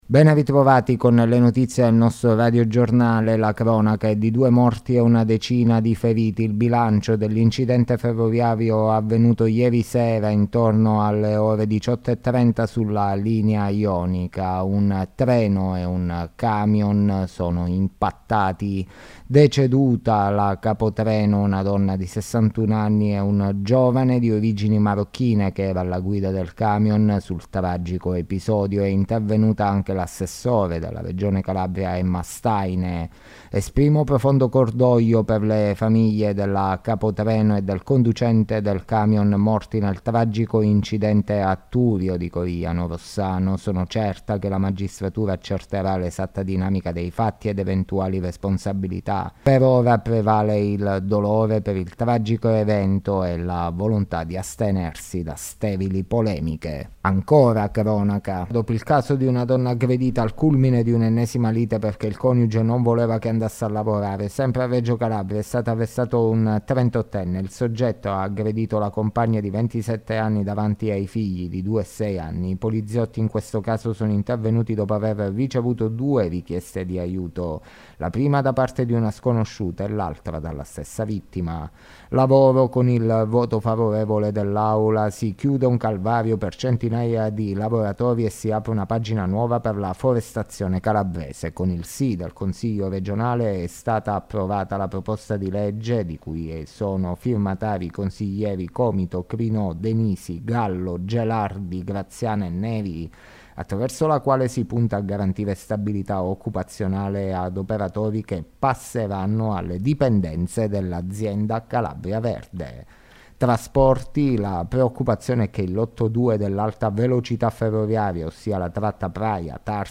Le notizie del giorno di Mercoledì 29 Novembre 2023